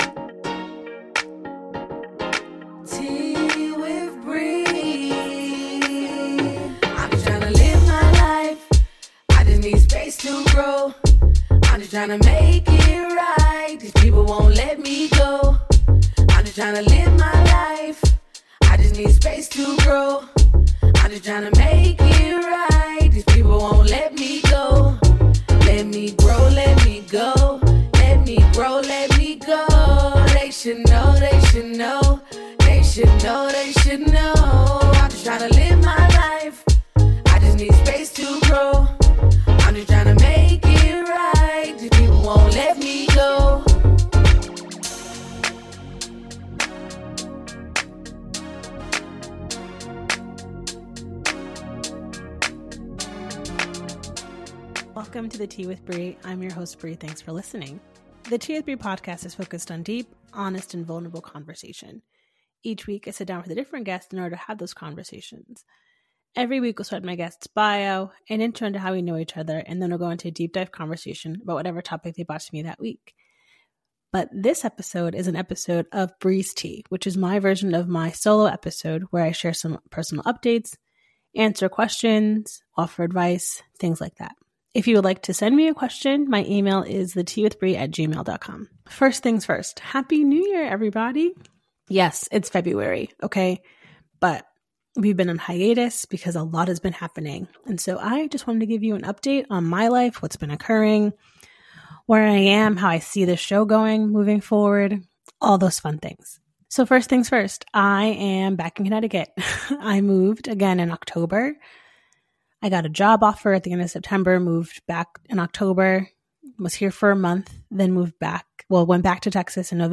This podcast was recorded via Riverside FM.